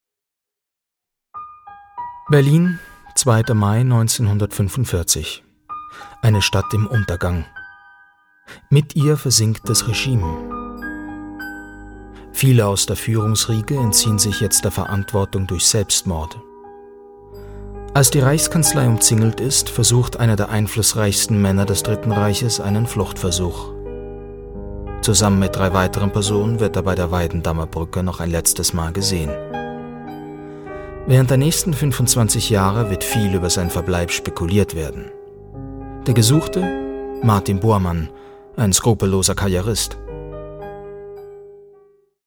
Grosse Bandbreite und Wandelbarkeit.
Der Aufnahme- und Regieraum ist zu 100% schalldicht.
Sprechprobe: Sonstiges (Muttersprache):